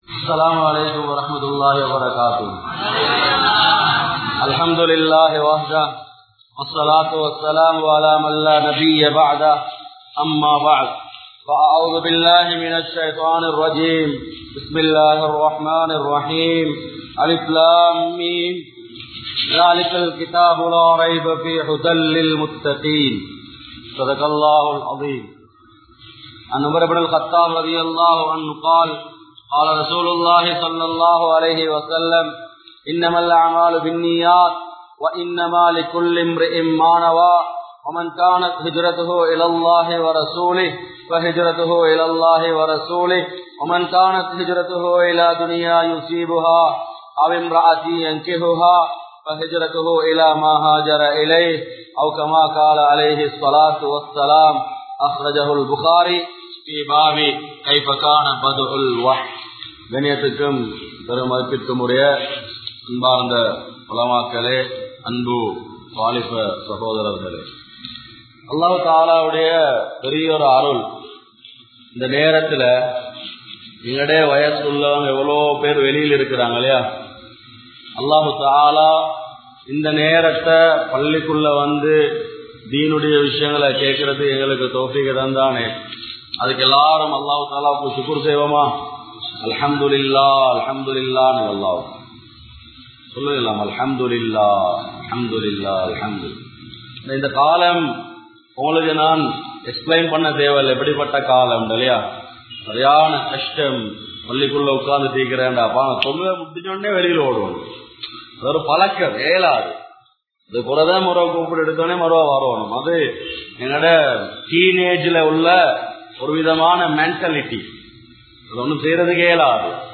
Allahvin Khutharath (அல்லாஹ்வின் குத்ரத்) | Audio Bayans | All Ceylon Muslim Youth Community | Addalaichenai